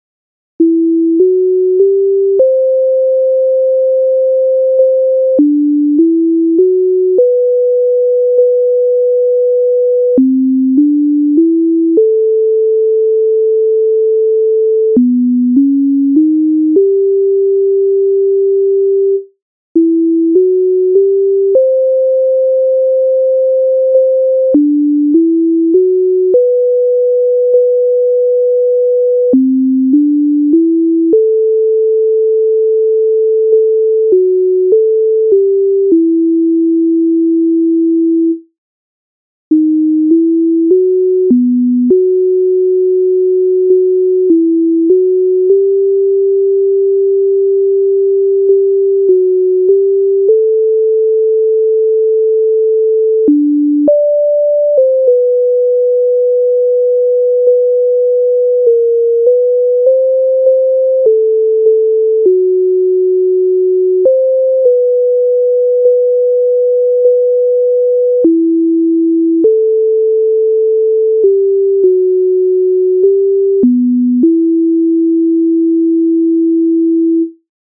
MIDI файл завантажено в тональності e-moll